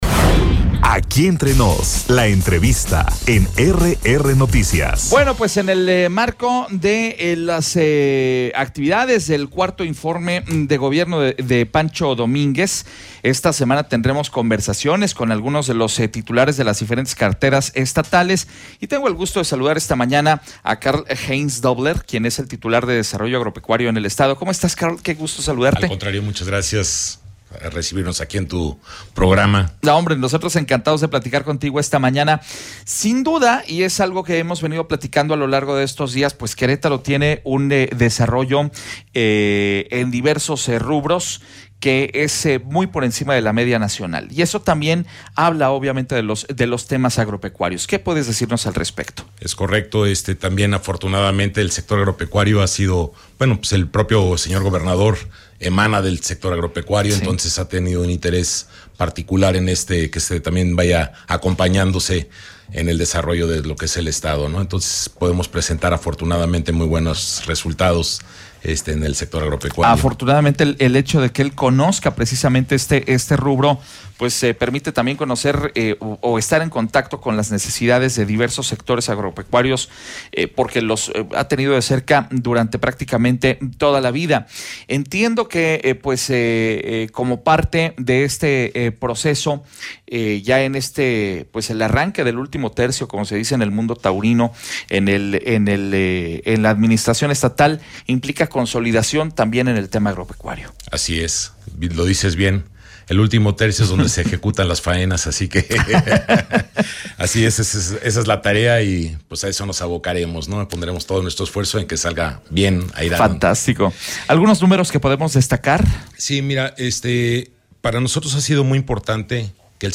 ENTREVISTA-SECRETARIO-DE-DESARROLLO-AGROPECUARIO-CARL-HEINZ.mp3